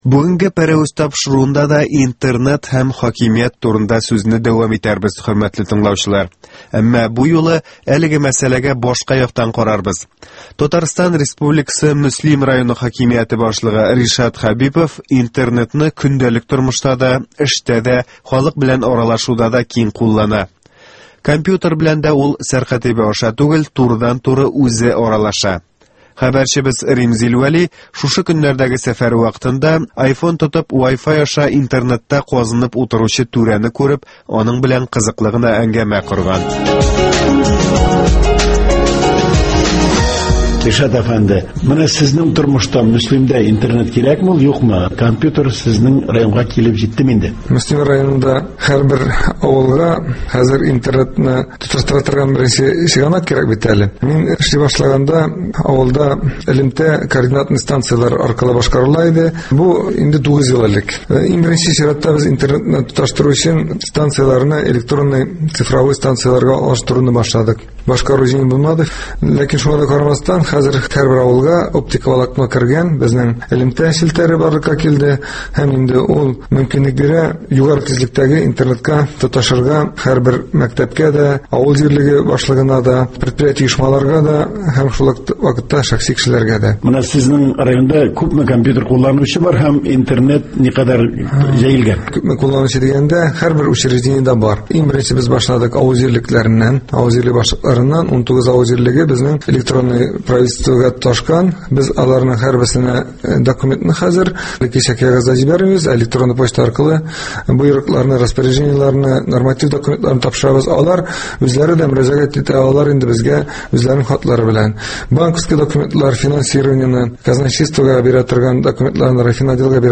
Мөслим районы хакимияте башлыгы Ришат Хәбипов белән әңгәмә